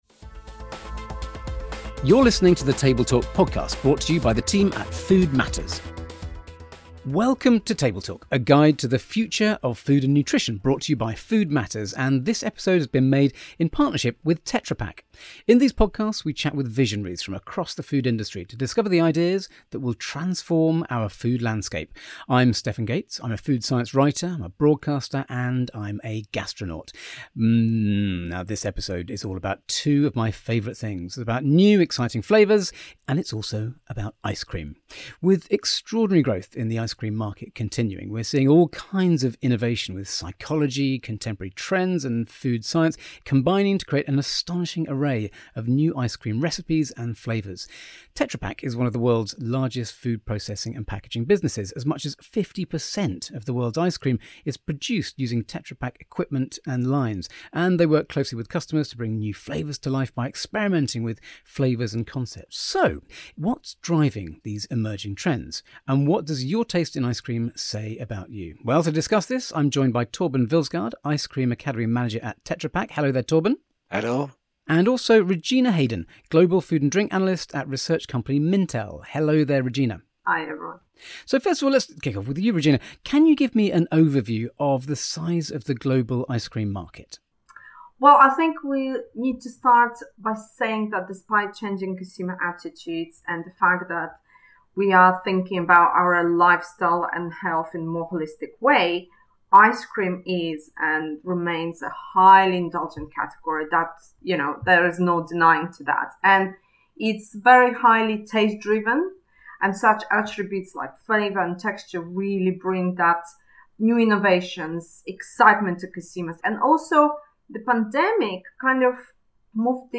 Is changing consumer demand leading to a surge in plant-based, healthier and more sustainable ice cream? To find out we’re joined by the true experts in the field, Tetra Pak and Mintel.